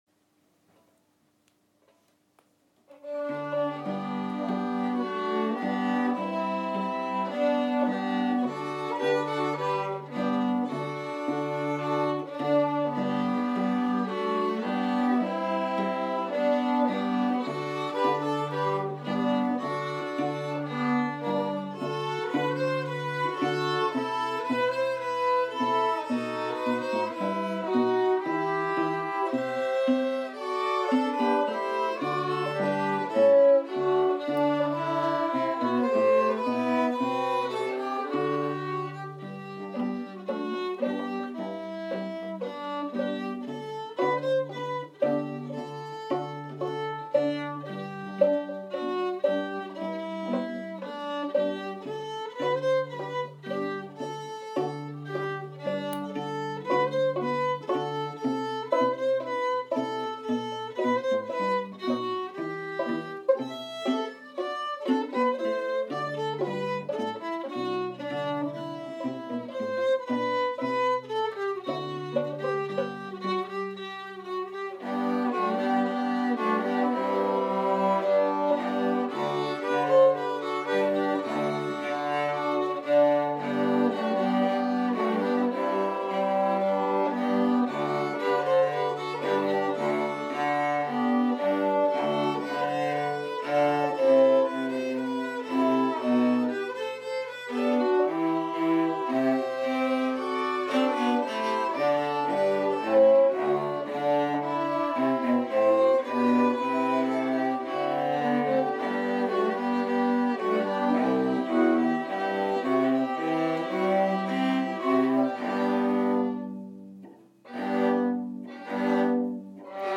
quartet v1 brahms